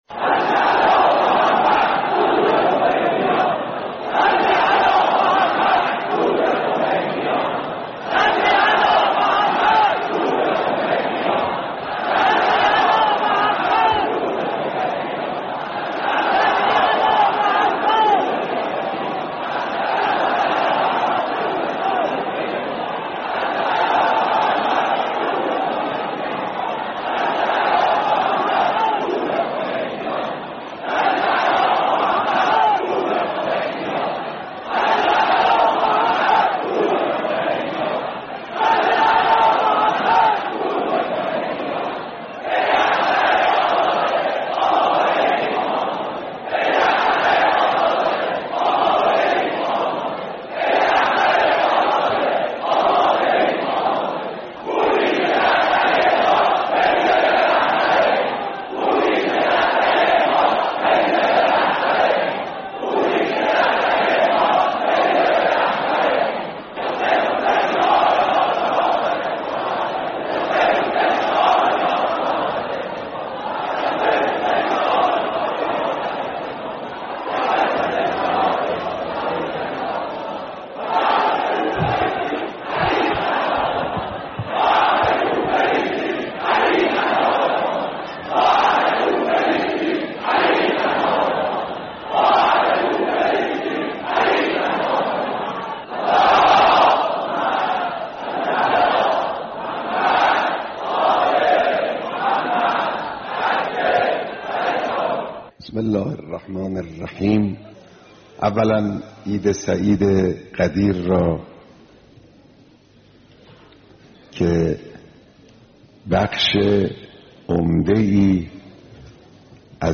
صوت کامل بیانات
بیانات در روز عید غدیر خم